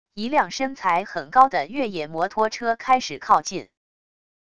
一辆身材很高的越野摩托车开始靠近wav音频